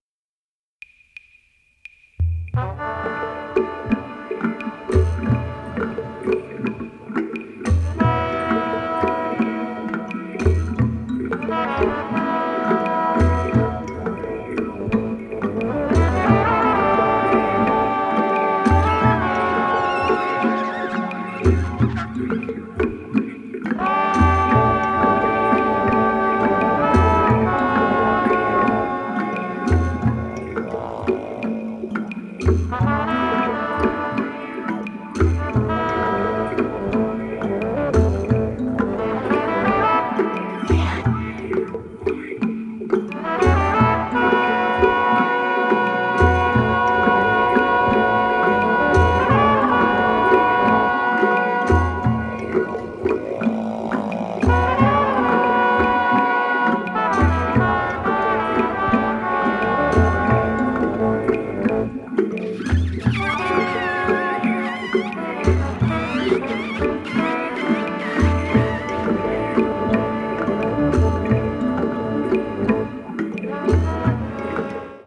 神秘的で土着的なアンビエント～ニュー・エイジな世界が◎！
民族音楽を経由したミニマルなアプローチが◎！